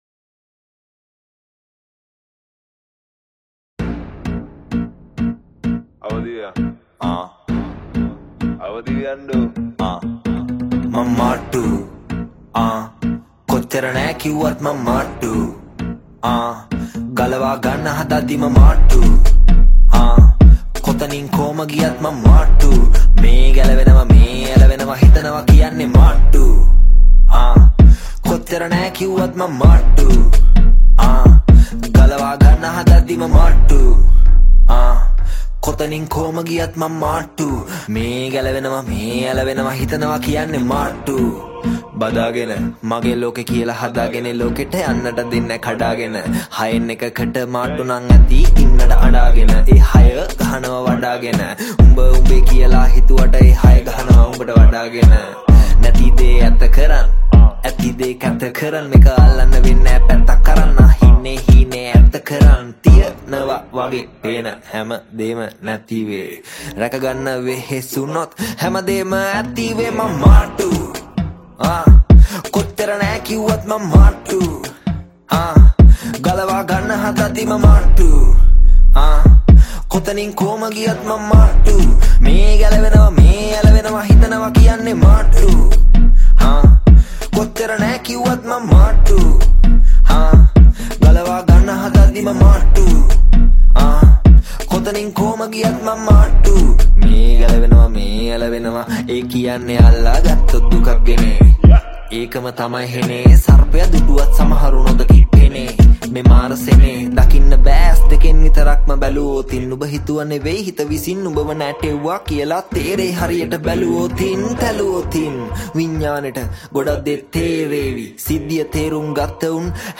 Sinhala Rap